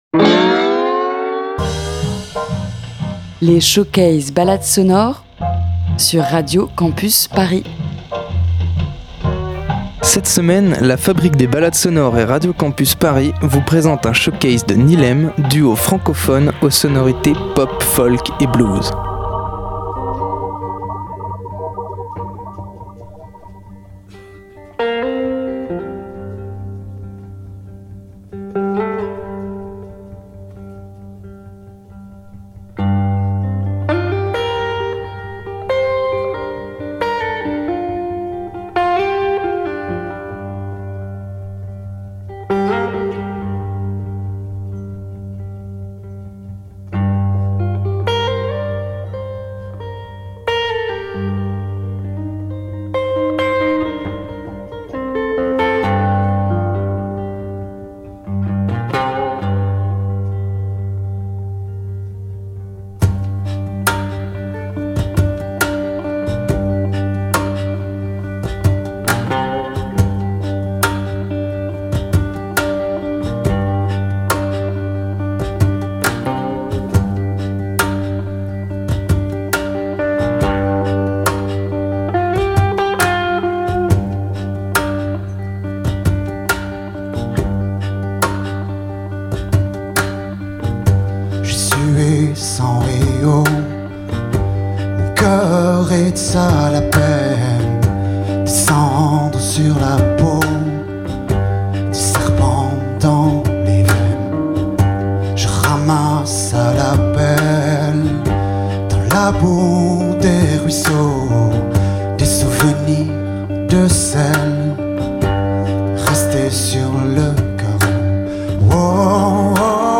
Réécoutez 3 titres du showcase